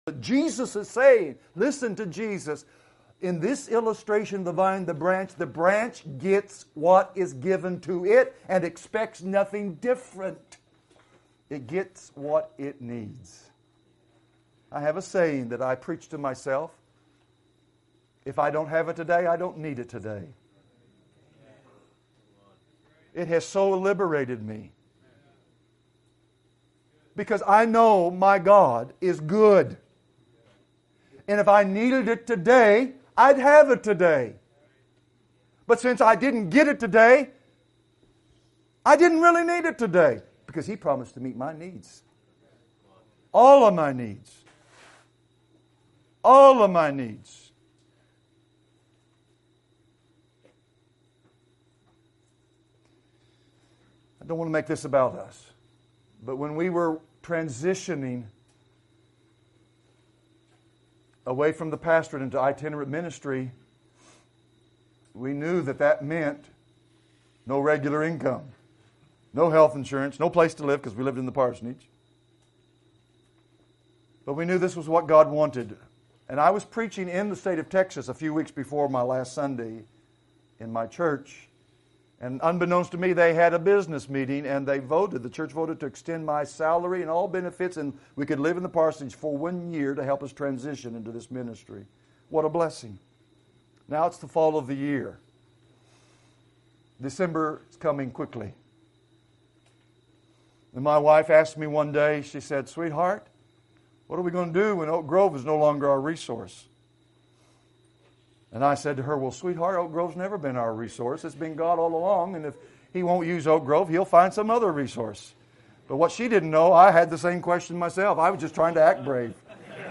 2020 Category: Excerpts In John 15 Jesus is saying that the branch will get what is given to it and what it needs.